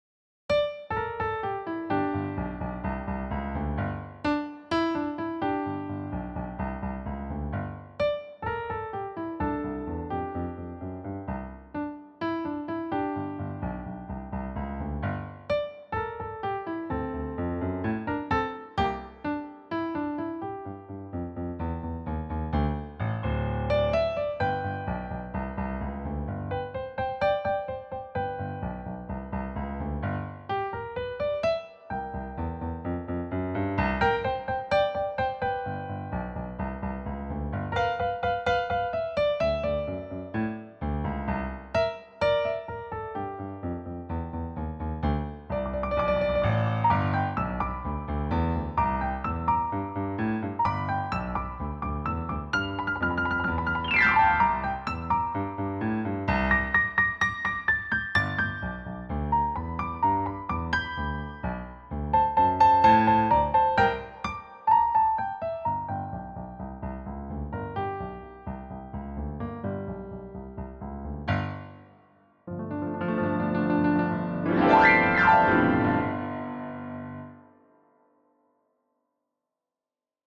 Key: G Major / G Blues
Time Signature: 4/4 (BPM ≈ 128)